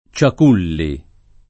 Ciaculli [ © ak 2 lli ]